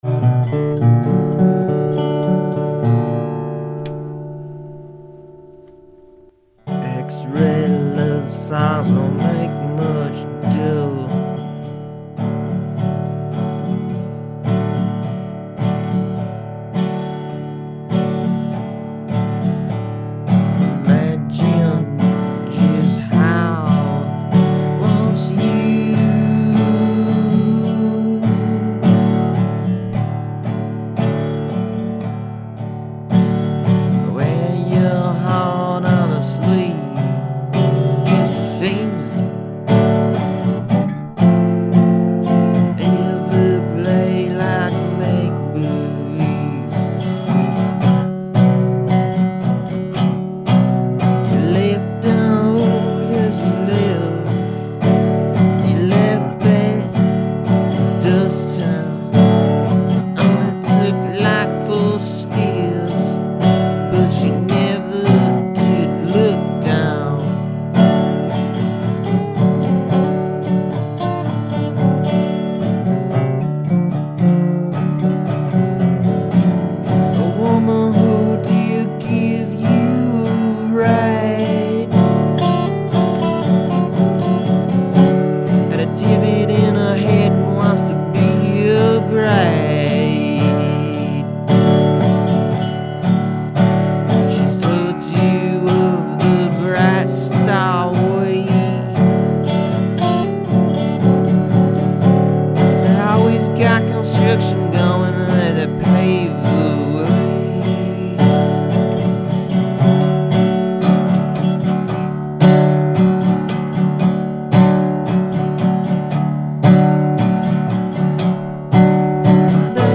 new, demos and upcoming ideas
warning:unfinished and/or lo-fi